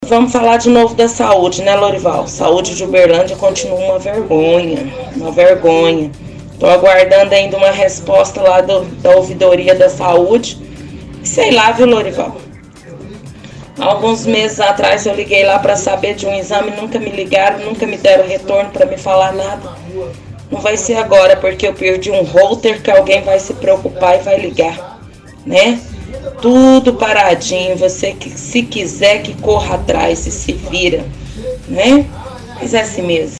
-Ouvinte reclama da saúde de Uberlândia diz que aguarda uma resposta da ouvidoria da saúde.